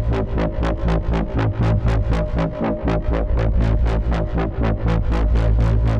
Index of /musicradar/dystopian-drone-samples/Tempo Loops/120bpm
DD_TempoDroneA_120-A.wav